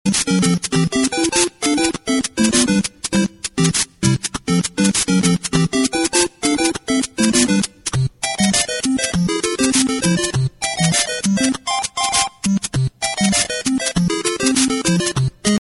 Kategorien Soundeffekte